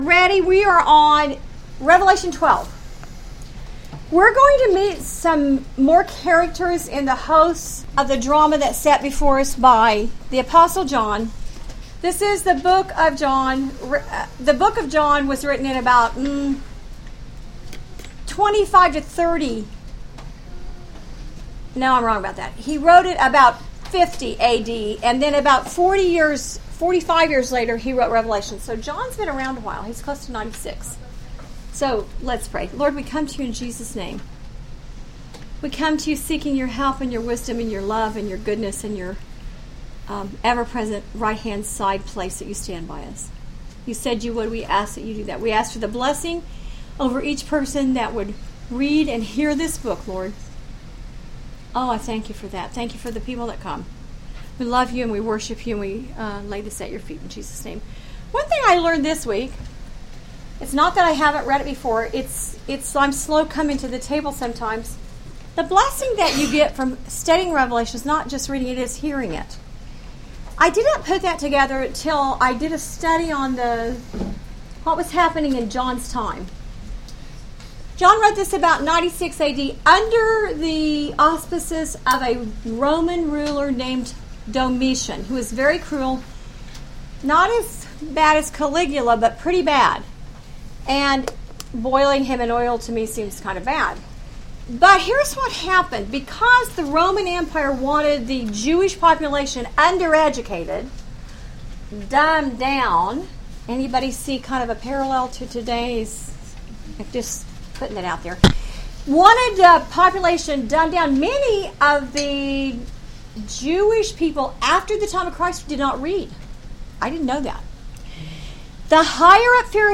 Bible Study Audio and Worksheets Revelation–Chapter 12 August 2